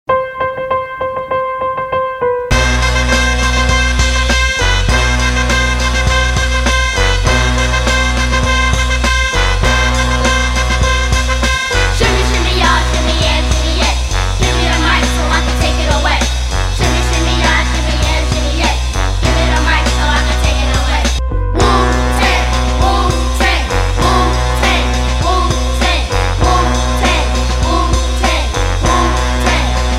Kategori Rap